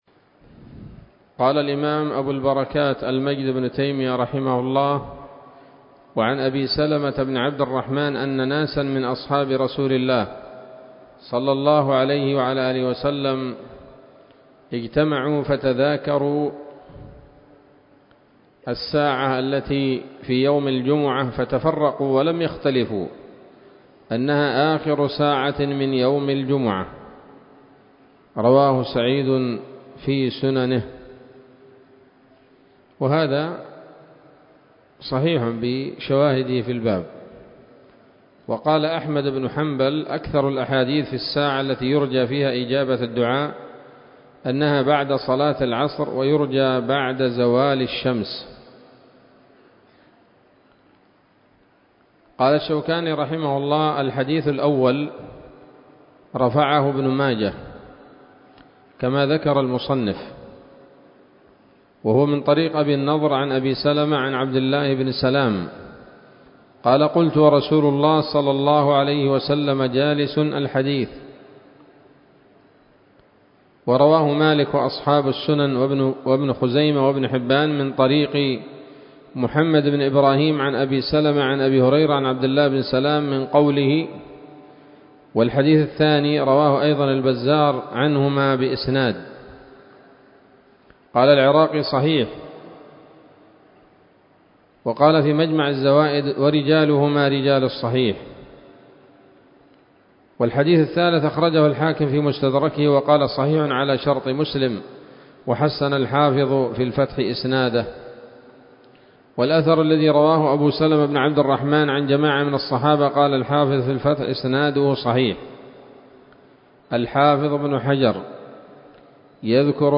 الدرس الرابع عشر من ‌‌‌‌أَبْوَاب الجمعة من نيل الأوطار